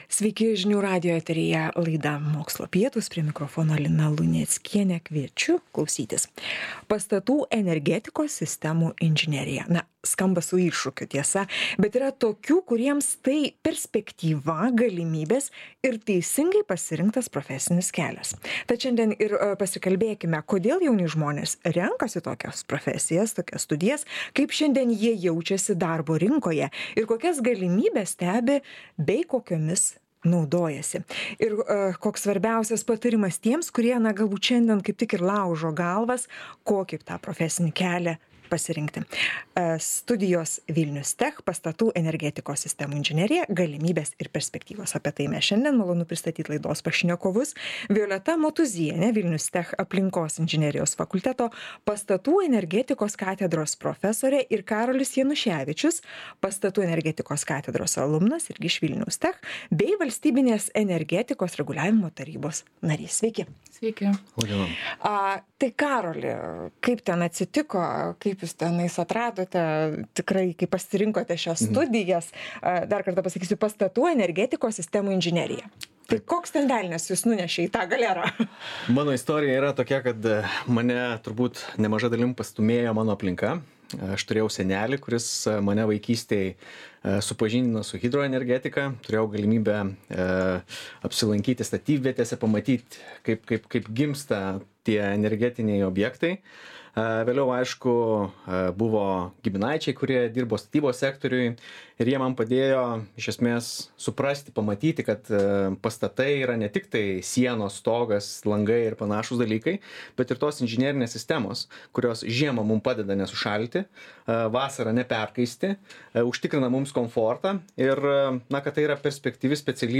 Laidoje dalyvauja VILNIUS TECH Aplinkos inžinerijos fakulteto atstovai